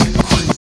99 SCRATCH.wav